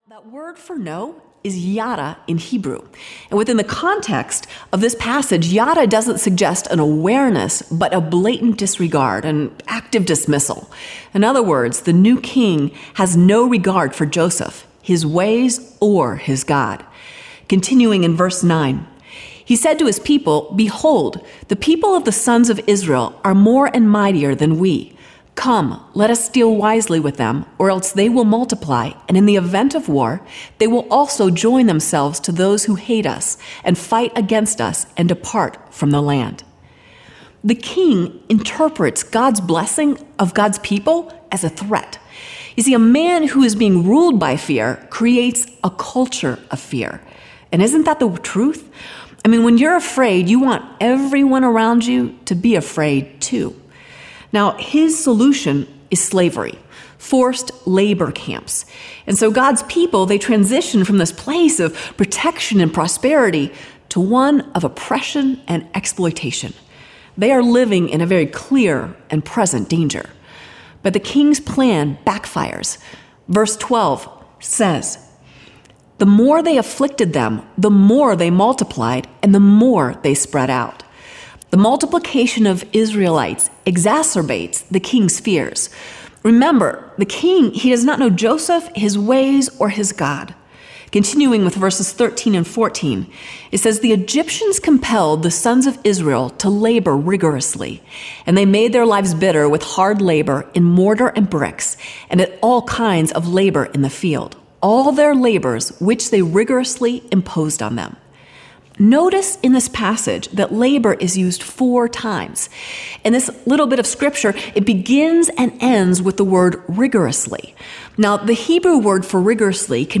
These sessions will reflect the ambiance of the unique recording locations, immersing the listener into the teaching.